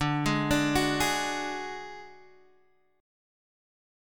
DMb5 chord